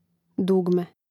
dùgme dugme